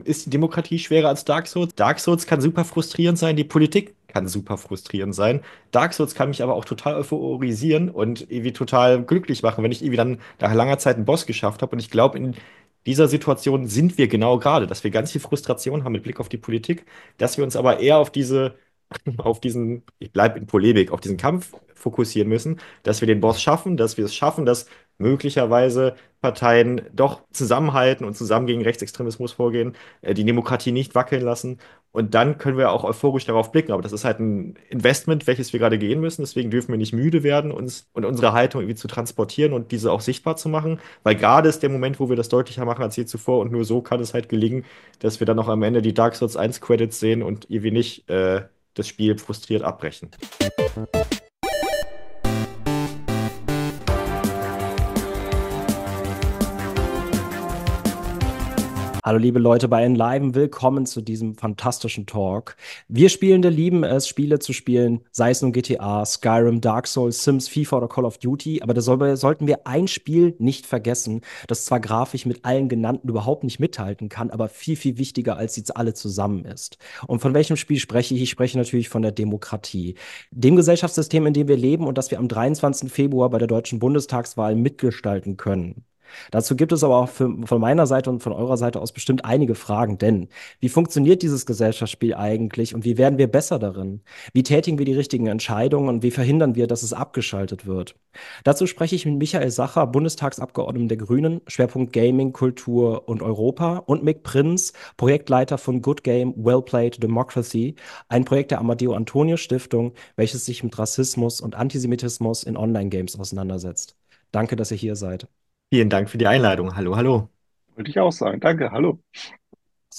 Ist Demokratie schwerer als Dark Souls? Wie werden wir besser darin und wie verhindern wir das Game Over? Dazu spreche ich mit zwei Experten und kläre, welche Erkenntnisse und Gedanken wir mitnehmen sollten, wenn wir am 23. Februar bei der deutschen Bundestagswahl unser Kreuz setzen.